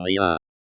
ʁjɛ̃/ “nothing”.